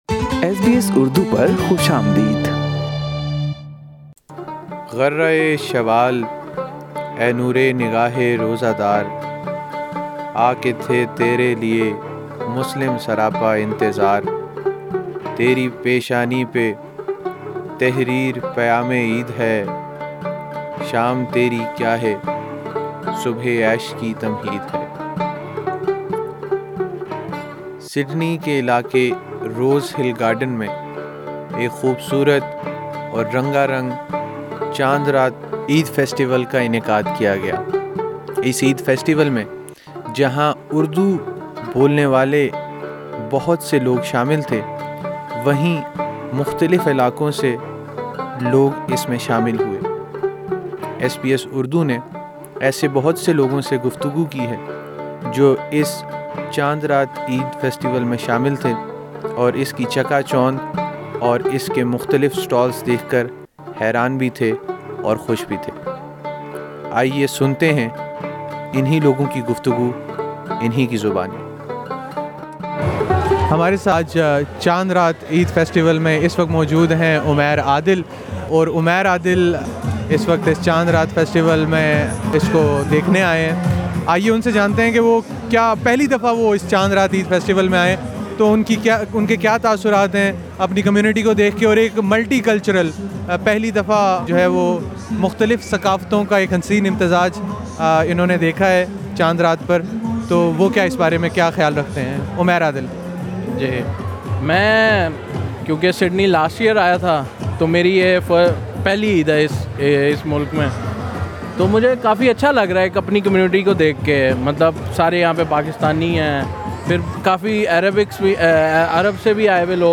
ایس بی ایس اردو نے اس میلے میں آنے والوں سے جو دلچسپ بات چیت کی اس کا لطف آپ بھی اٹھائیے۔۔